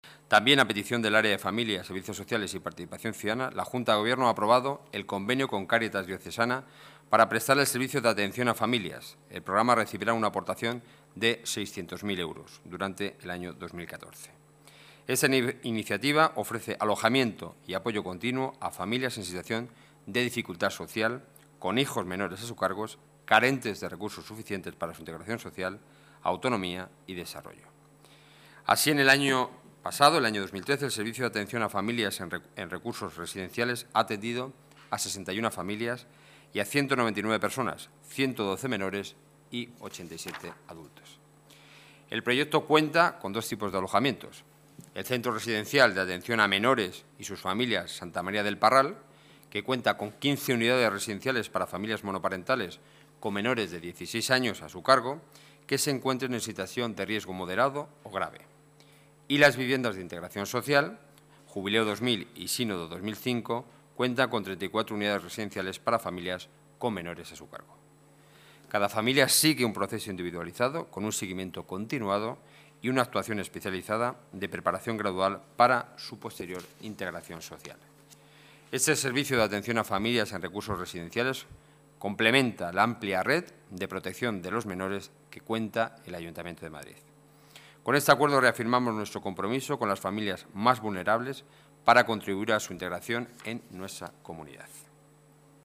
Nueva ventana:Declaraciones portavoz Gobierno municipal, Enrique Núñez